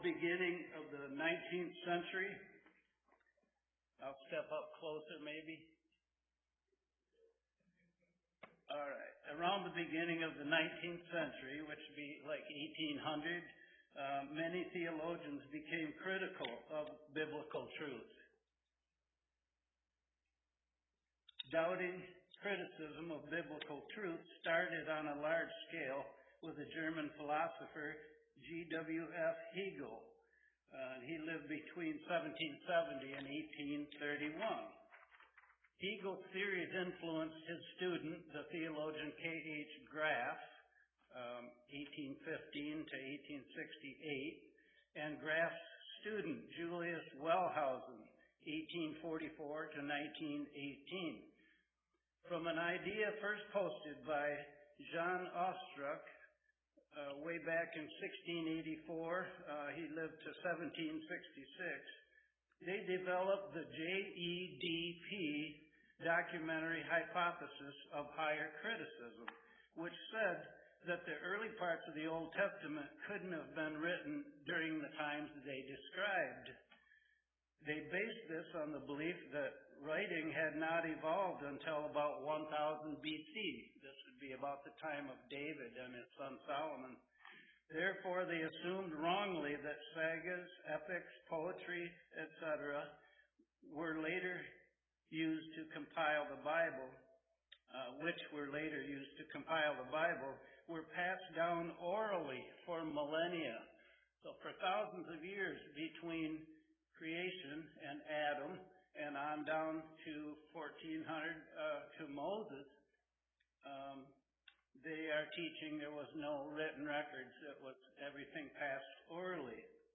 Grace-Life-Bible-Church-Live-Strea1.m4a